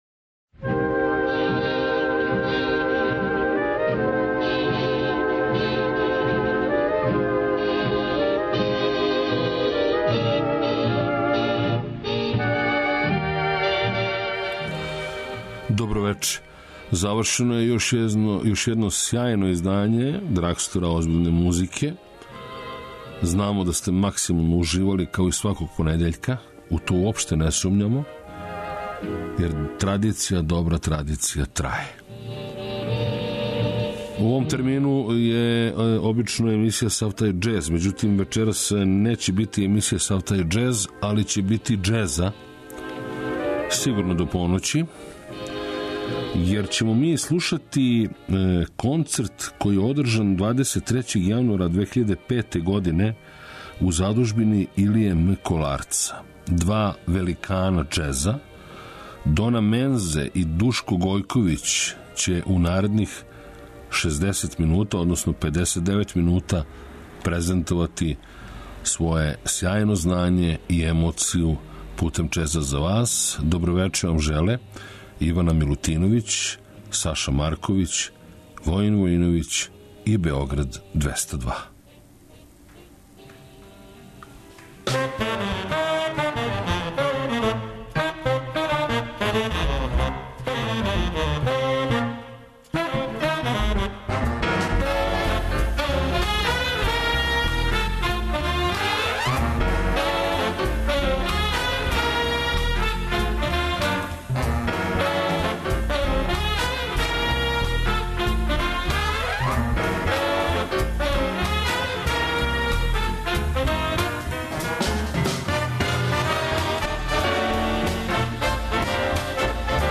Емисија је магазинског типа и покрива све правце џез музике, од Њу Орлиенса, преко мејнстрима, до авангардних истраживања. Теме су разноврсне - нова издања, легендарни извођачи, снимци са концерата и џез клубова, архивски снимци...